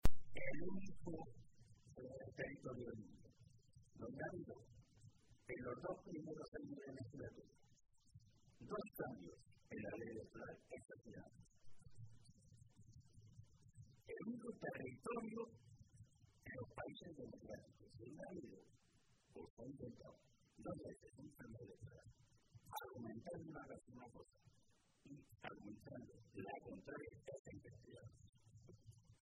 Jesús Fernández Vaquero, Secretario de Organización del PSCM-PSOE
Cortes de audio de la rueda de prensa